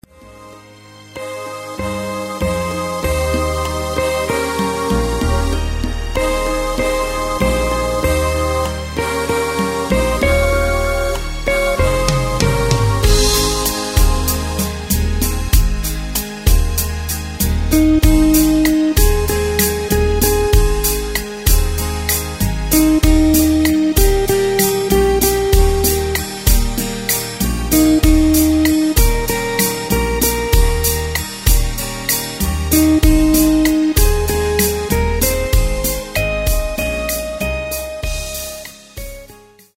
Takt:          4/4
Tempo:         96.00
Tonart:            Ab
Schöne Ballade aus dem Jahr 1998!
Playback mp3 Demo